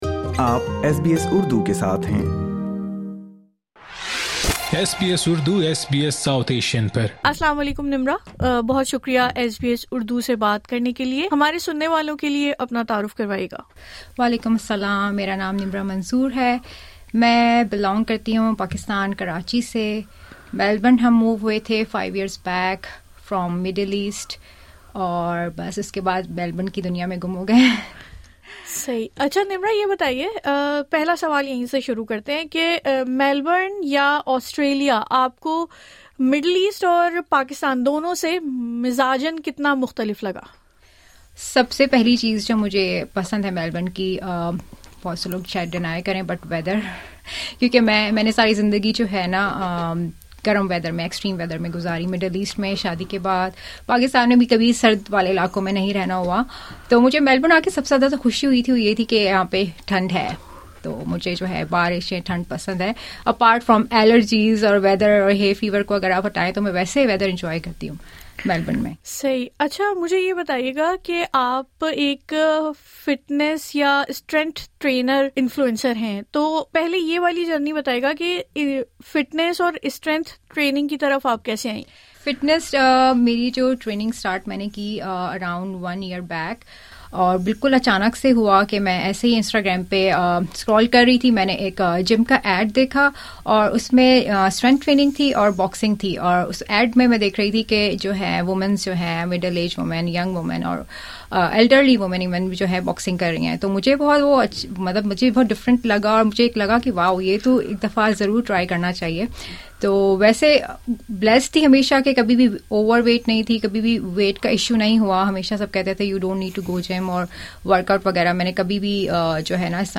ان کی ایس بی ایس اردو سے گفتگو سنئے اس پوڈ کاسٹ میں۔